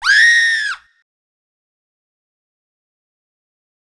Scream 1.wav